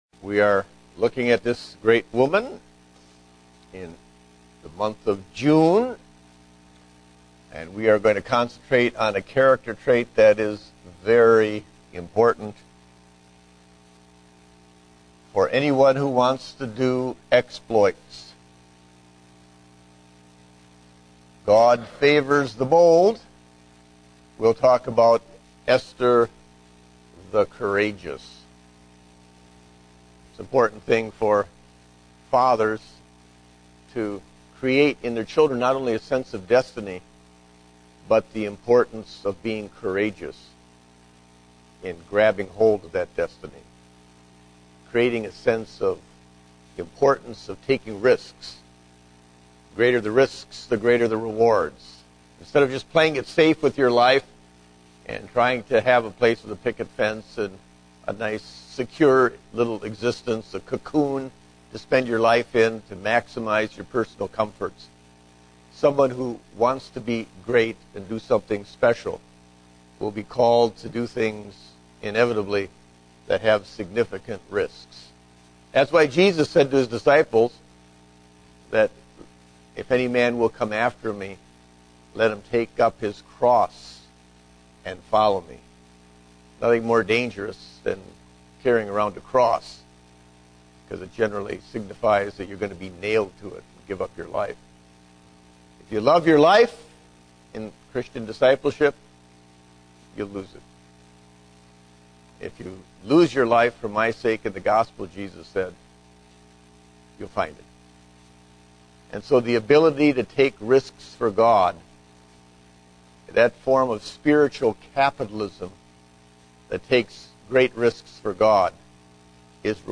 Date: June 20, 2010 (Adult Sunday School)